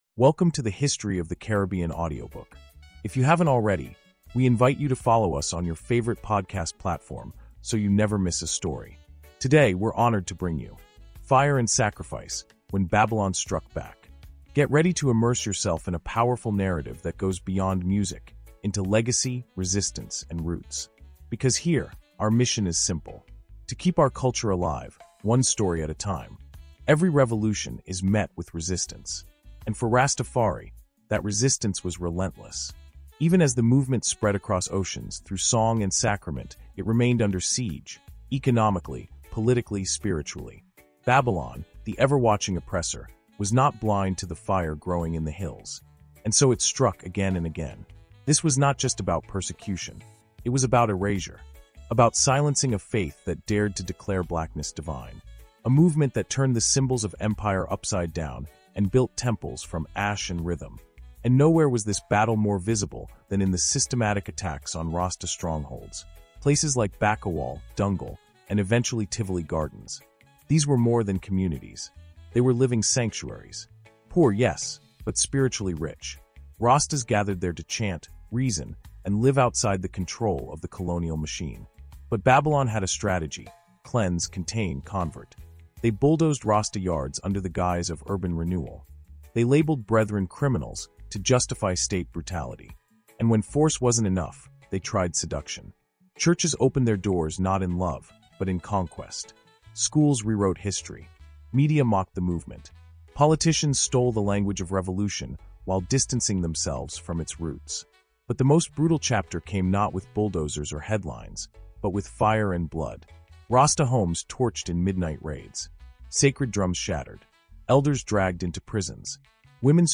Fire and Sacrifice – When Babylon Struck Back | Audiobook Insight